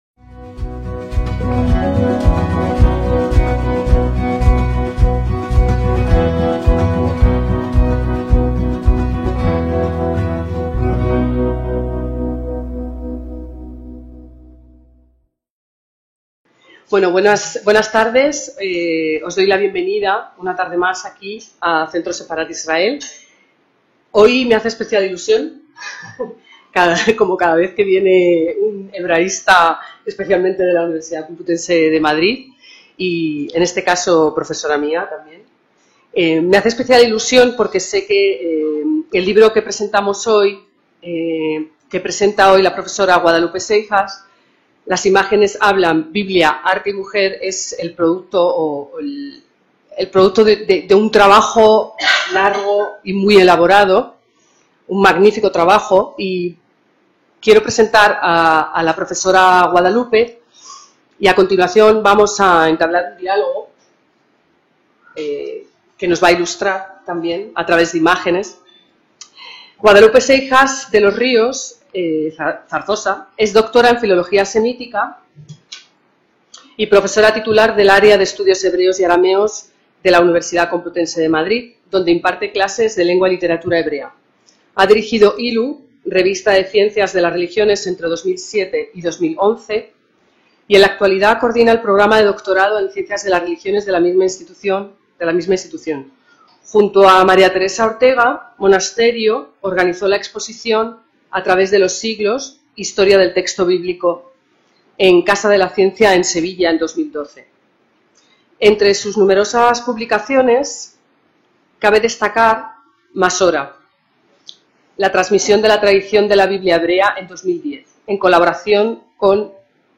ACTOS EN DIRECTO - El 29 de mayo de 2025 se presentó en la sede madrileña del Centro Sefarad Israel el libro "Las imágenes hablan.